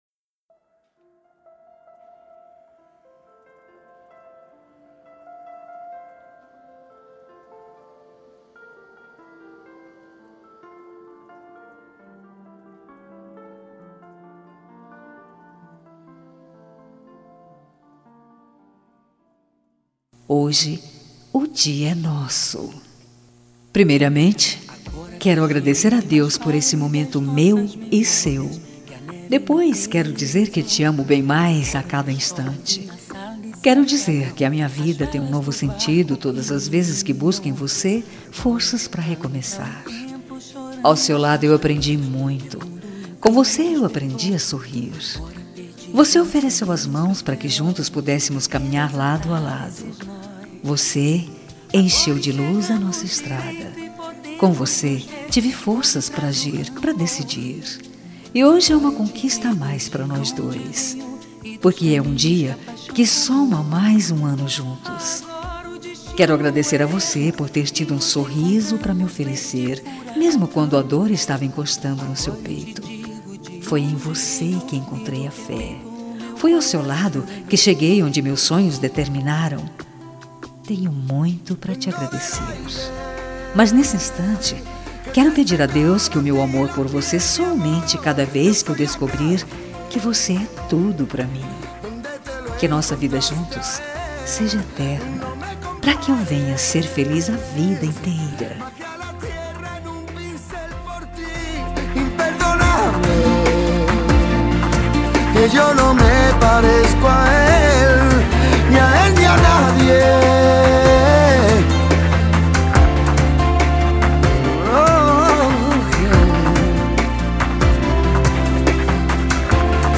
Voz Feminina